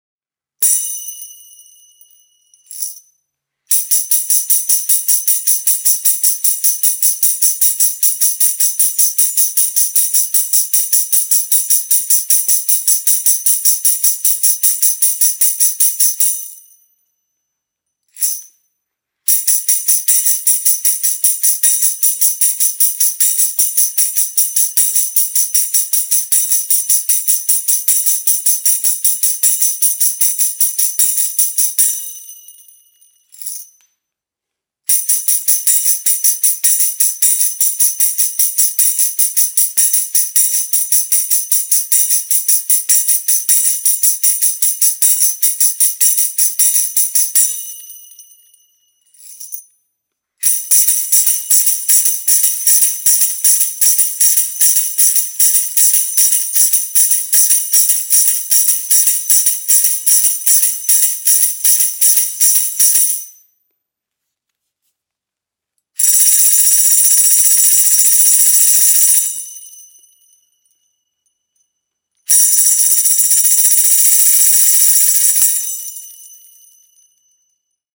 MEINL Percussion Traditional Wood Series Tambourine - 10" (TA2M-SNT)
The MEINl Recording-Combo Wood Tambourines create a whole new sound color. It is both dry and bright.